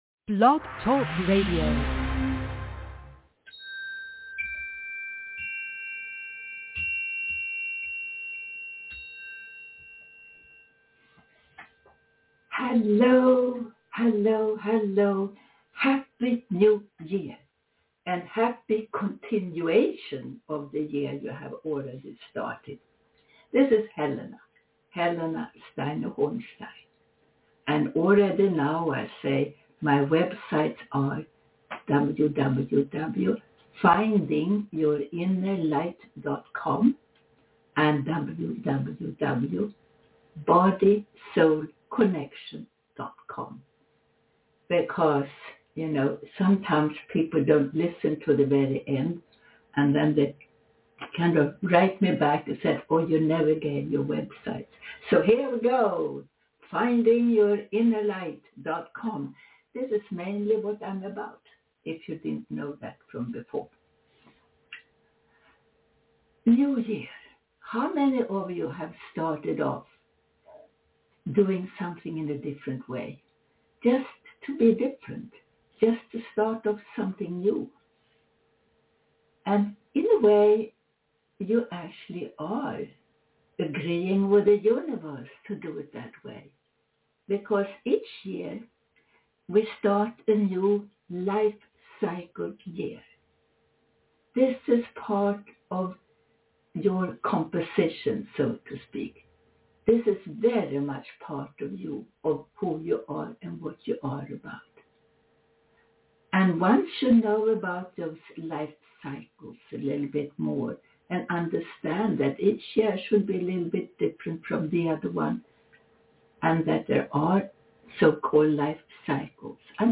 All shows include a channeled Power Meditation.